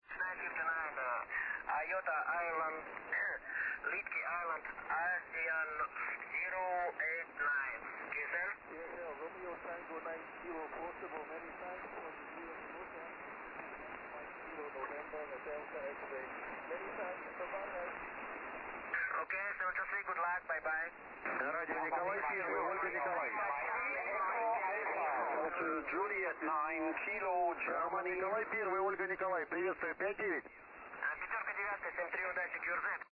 RT9K/P AS-089 20 SSB